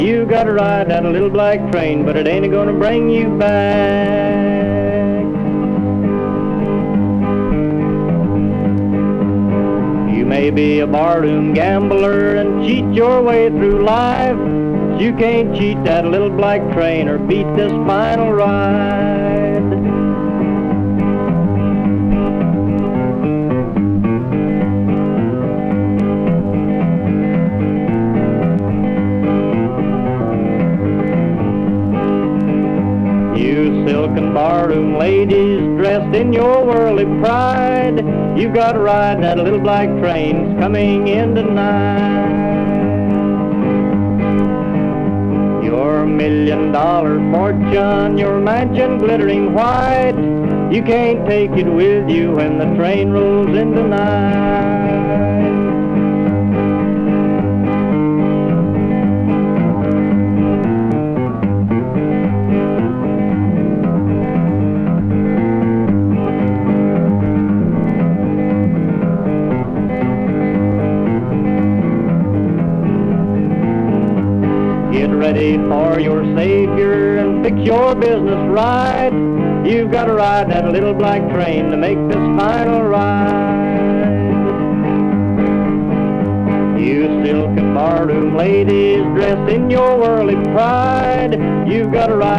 SSW / FOLK